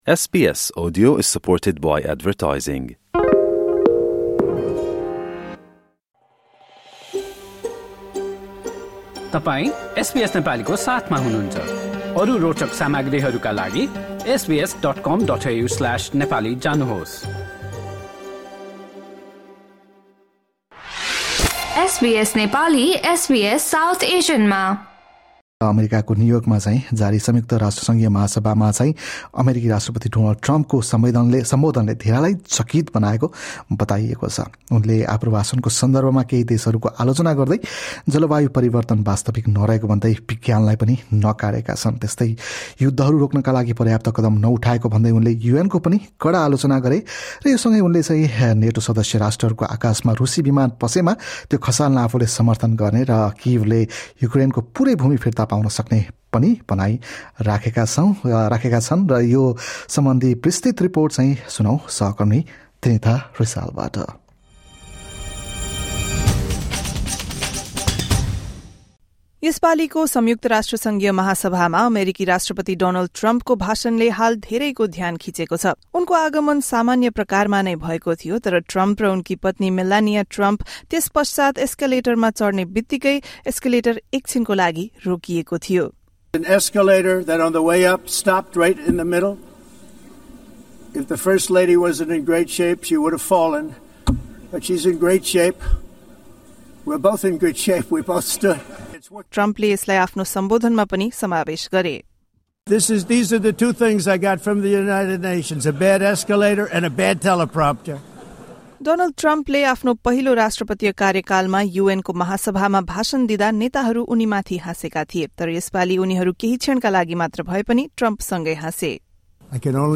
एक रिपोर्ट।